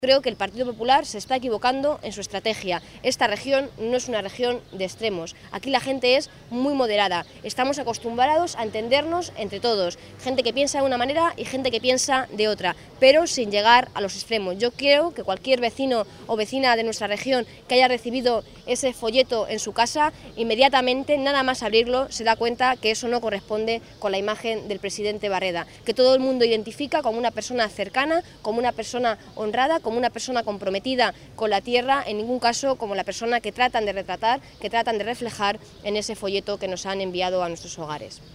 Isabel Rodríguez, atendiendo a los medios